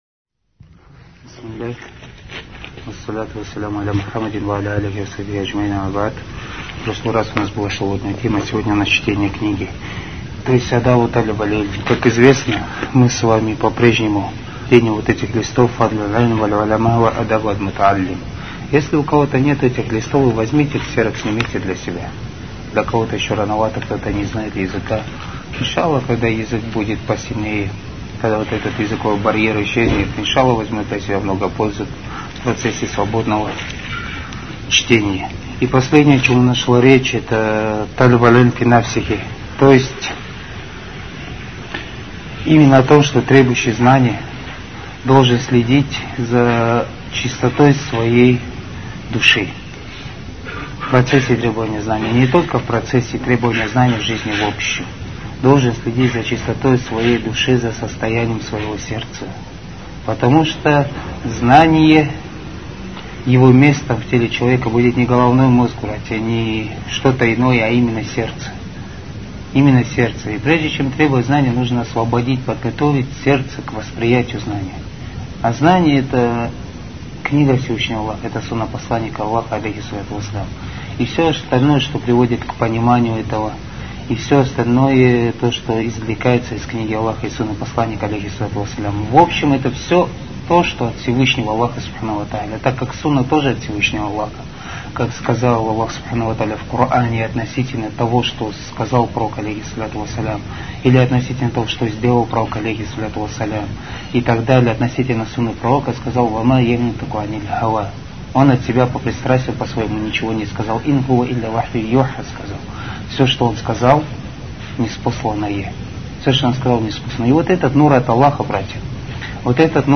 Материал: лекция шейха Салиха бин Абдуль Азиза Содержание: из того, что мешает требовать знание- высокомерие